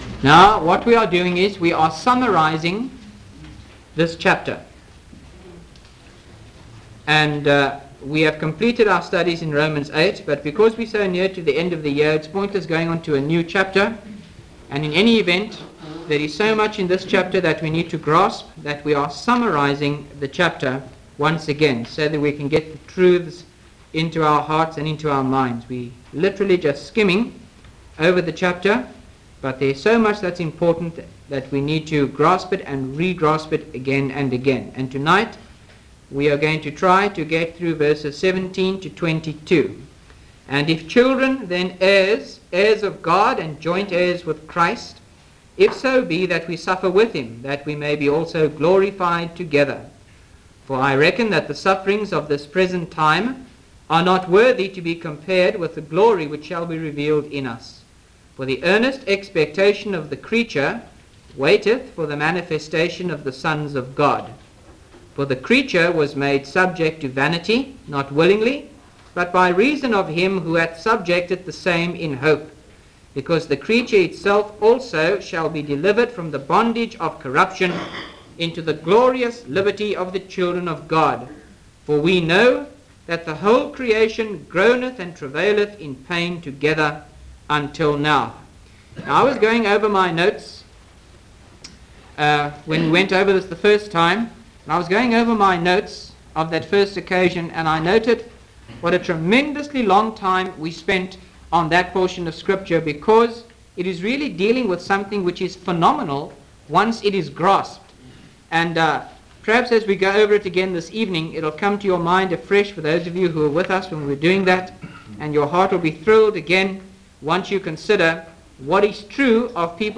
by Frank Retief | Feb 3, 2025 | Frank's Sermons (St James) | 0 comments